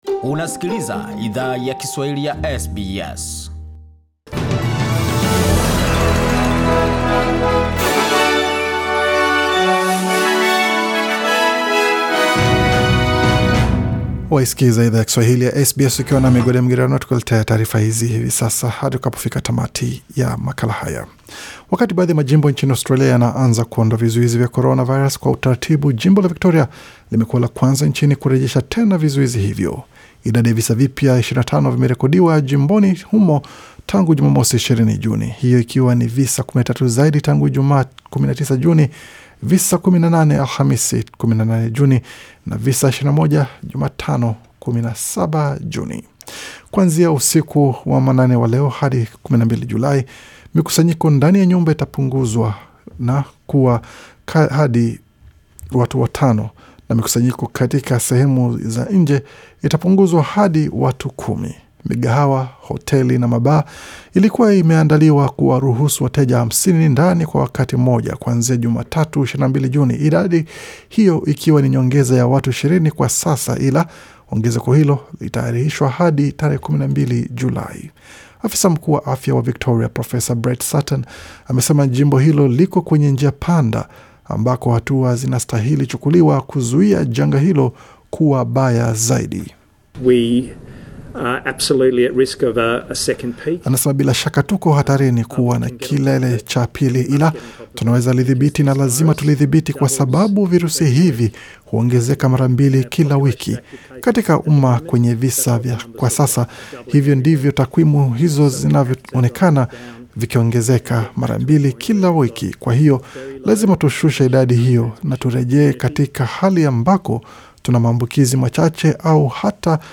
Taarifa ya habari 21 Juni 2020